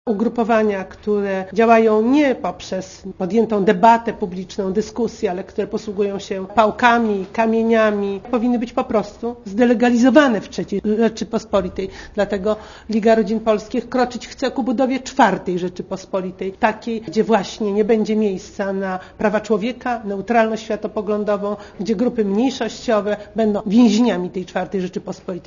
* Mówi Izabela Jaruga-Nowacka*